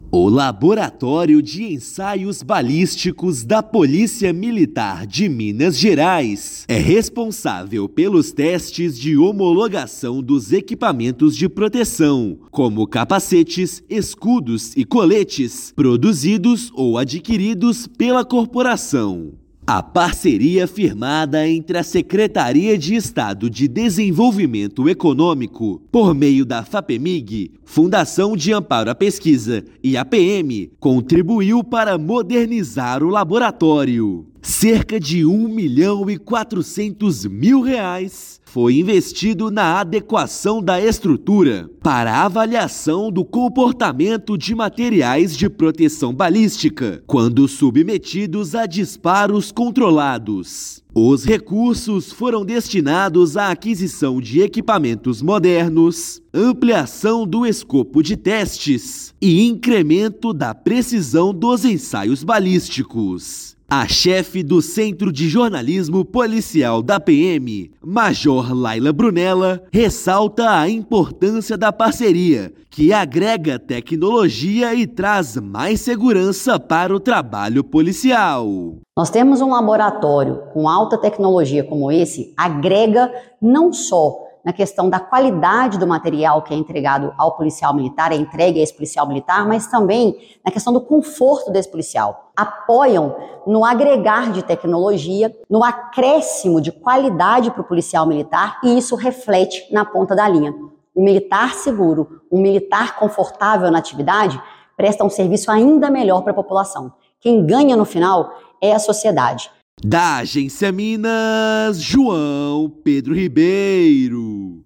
Local é responsável pelos testes de homologação de equipamentos de proteção balística, como capacetes, escudos e coletes. Ouça matéria de rádio.